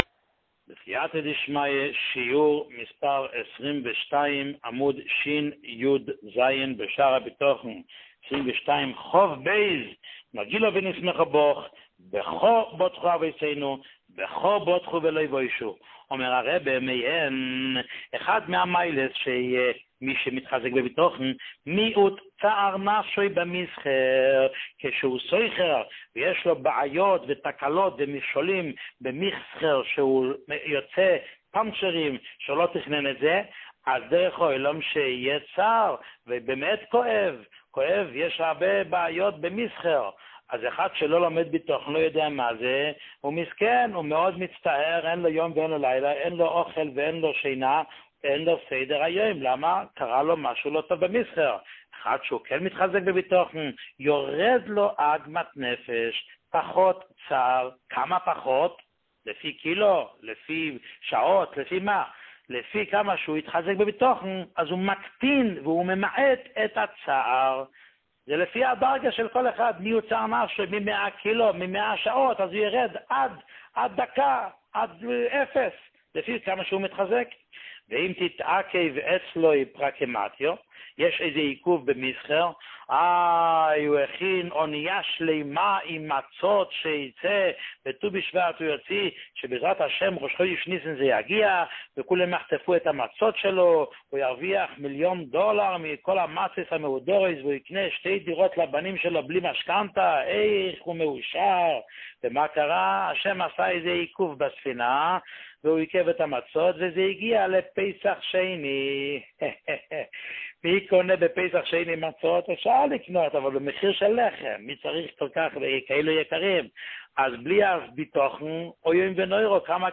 שיעורים מיוחדים
שיעור 22